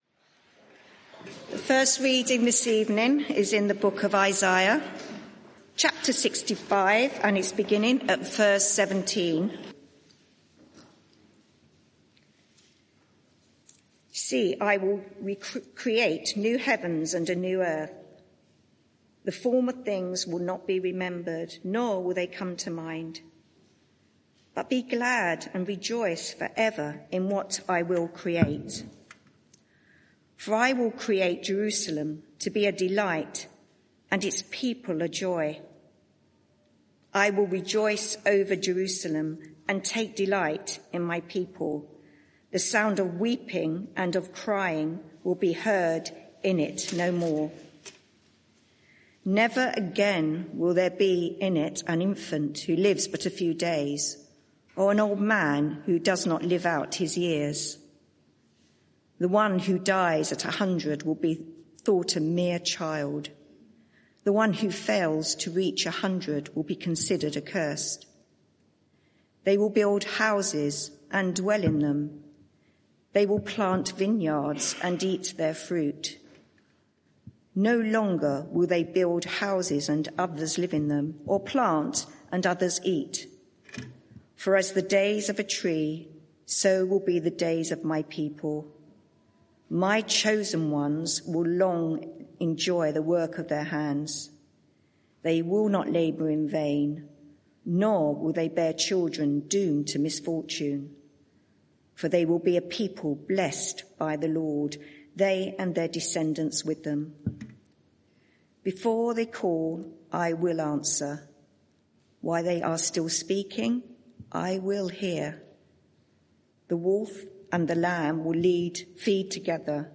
Media for 6:30pm Service on Sun 27th Apr 2025 18:30 Speaker
Sermon (audio) Search the media library There are recordings here going back several years.